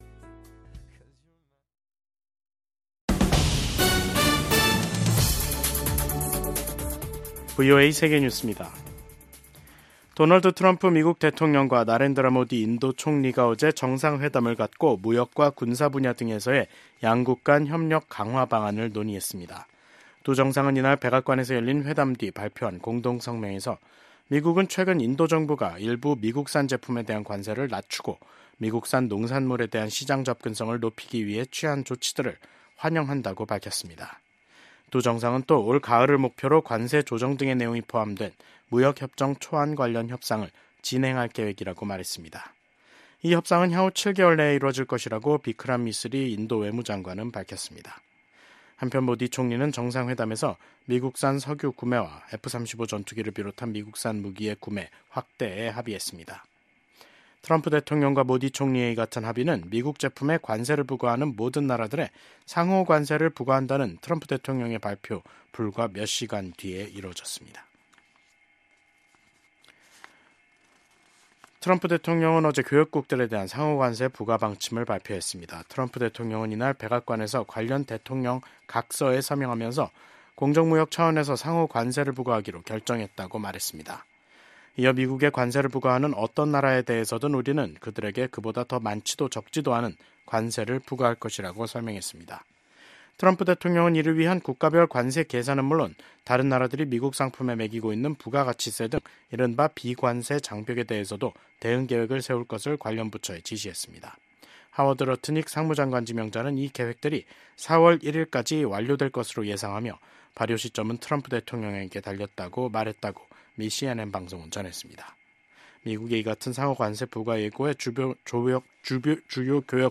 VOA 한국어 간판 뉴스 프로그램 '뉴스 투데이', 2025년 2월 14일 3부 방송입니다. 북한이 남북 화해의 상징인 금강산 관광지구 내 이산가족면회소를 철거 중인 것으로 파악됐습니다. 미국 군함을 동맹국에서 건조하는 것을 허용하는 내용의 법안이 미국 상원에서 발의됐습니다. 미국 경제 전문가들은 트럼프 대통령의 철강∙알루미늄 관세 부과는 시작에 불과하다면서 앞으로 더 많은 관세가 부과돼 미한 경제 관계에 긴장이 흐를 것으로 전망했습니다.